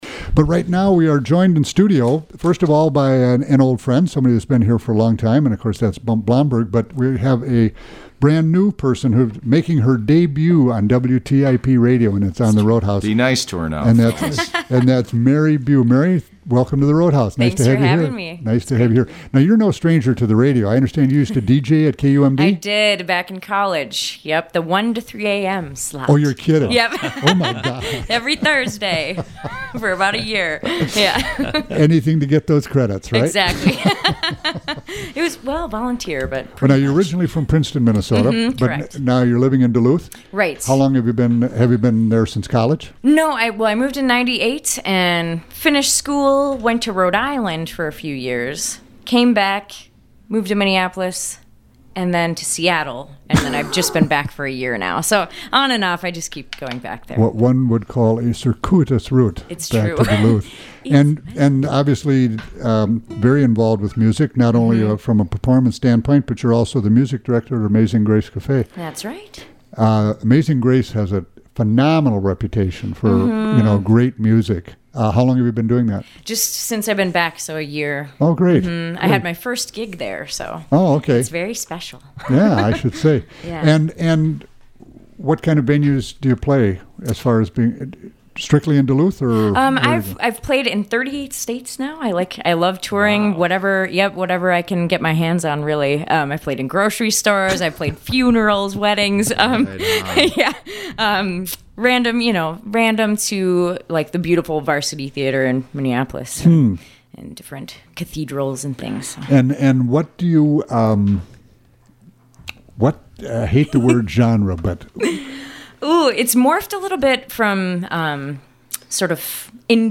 keyboard
Live Music Archive